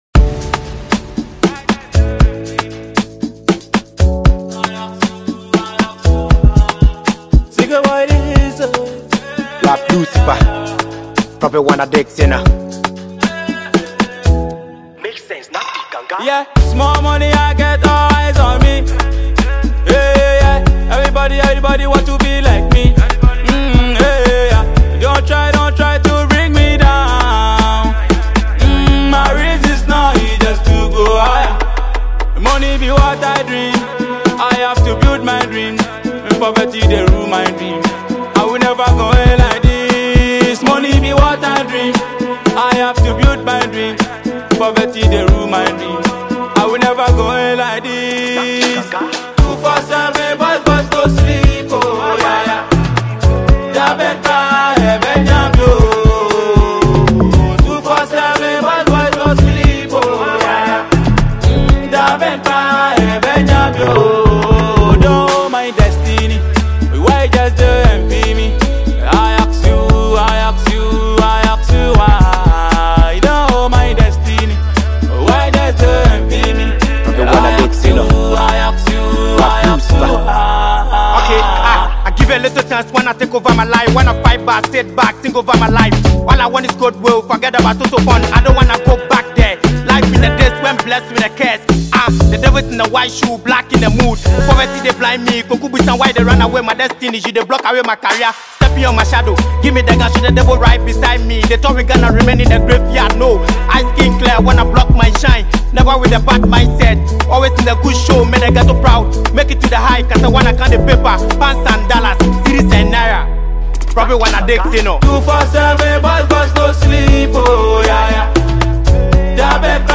melodious voice
hardcore rap